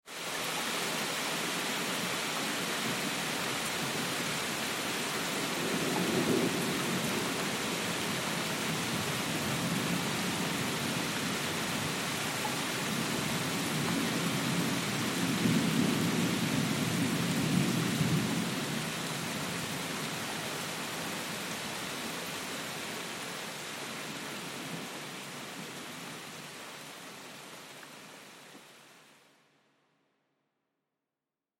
دانلود آهنگ رعد و برق 1 از افکت صوتی طبیعت و محیط
دانلود صدای رعد و برق 1 از ساعد نیوز با لینک مستقیم و کیفیت بالا
جلوه های صوتی